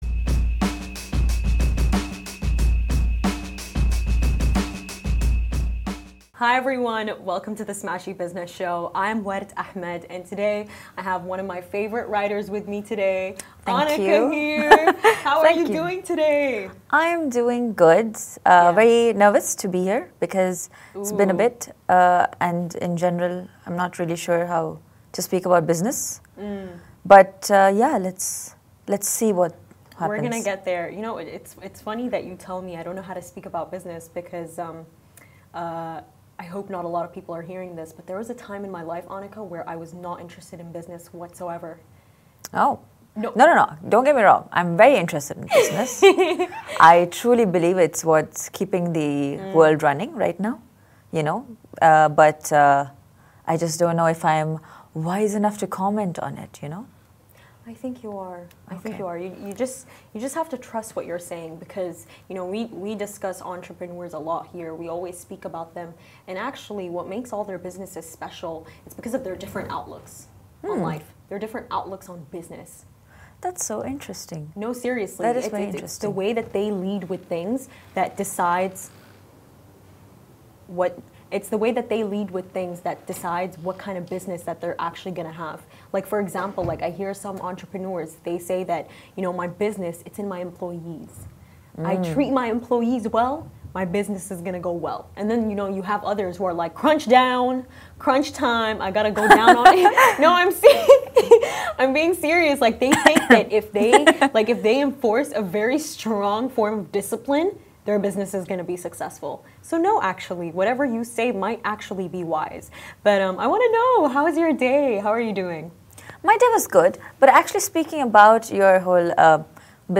The Smashi Business Show is where Smashi interviews the business leaders who make a difference in this great city.